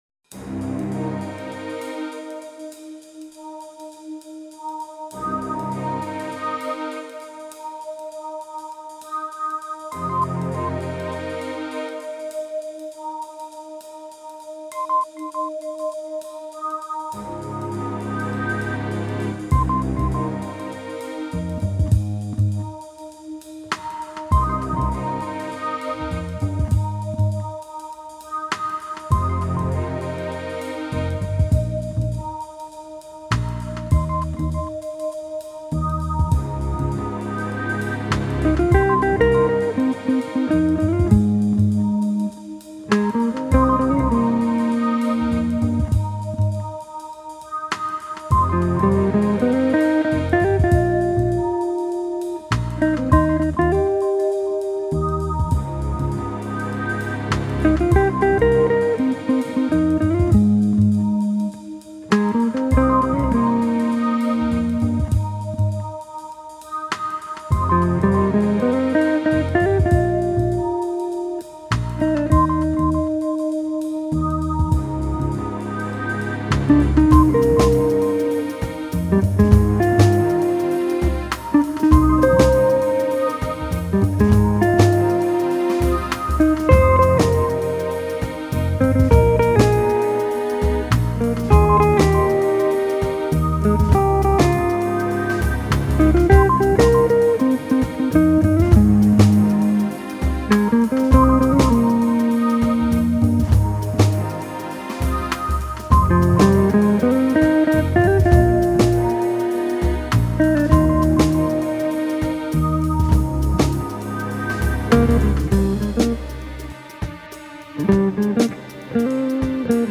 Guitar
Keyboards & programming
Acoustic bass